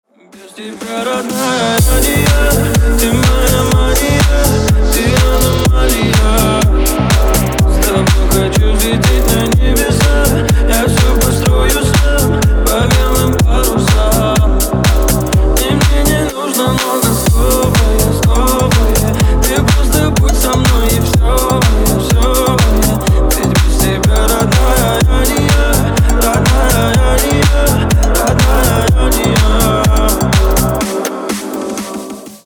• Качество: 320, Stereo
мужской вокал
громкие
deep house
dance
EDM
Club House
электронная музыка